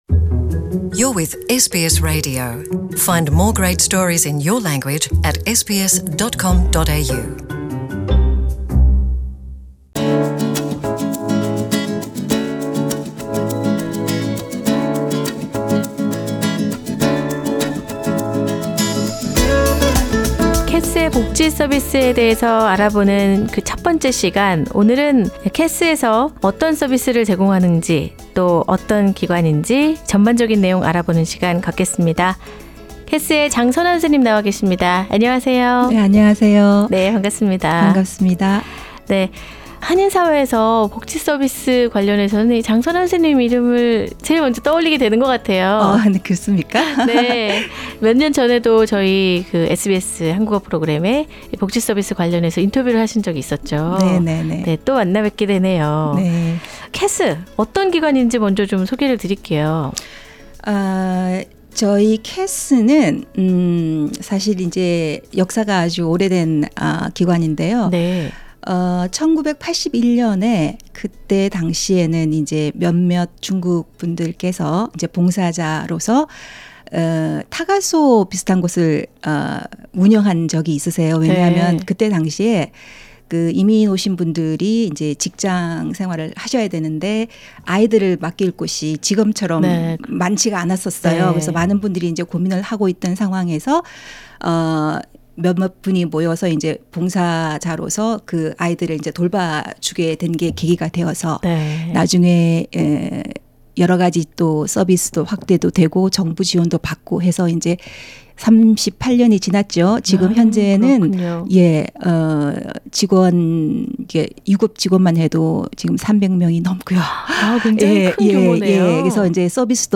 [The full interview is available on the podcast above] Share